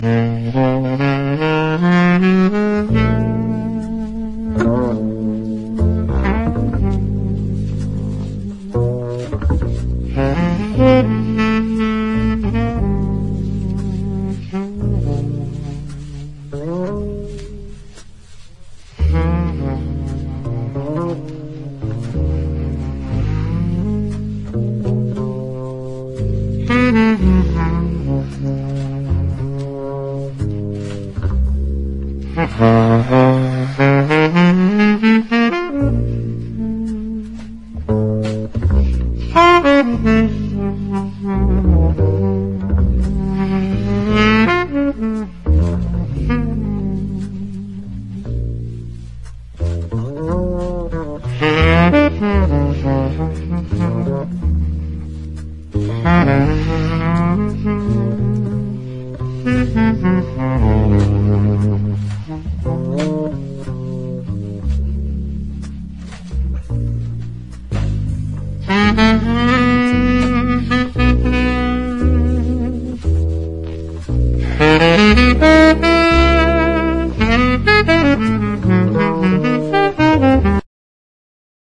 スキャット踊るユーロ・ボッサ/ラテン・ラウンジ・コーラス！
作品通してタイト且つ洒脱なアレンジが行き渡った軽妙ラウンジ・ジャズ・コーラスの連続！